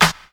Dr Dre Claps+Snaps_35.wav